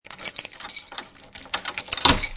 unlock.e2a3fd97d44f22739e50.mp3